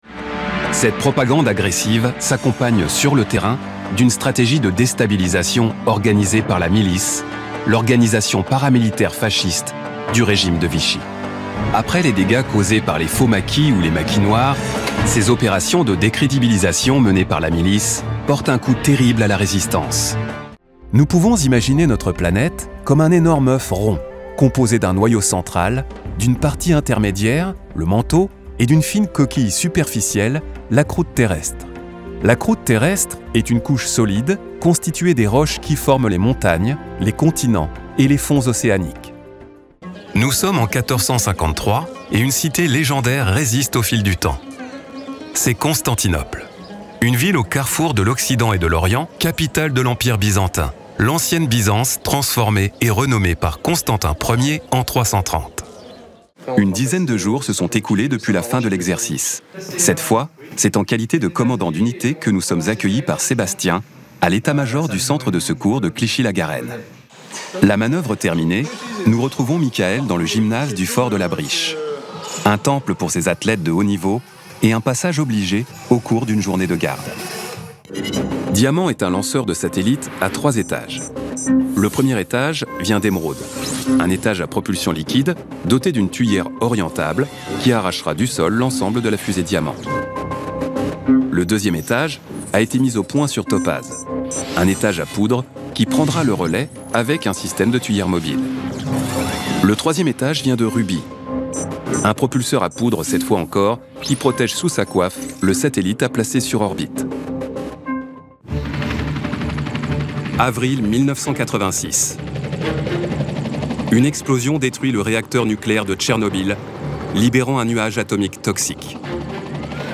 Native French male voice actor specialising in documentary narration, TV magazines and podcasts. Authoritative, measured or captivating — listen to audio demos online.
Voice Over Demos — TV Documentaries
French Voice Over – Documentary Narration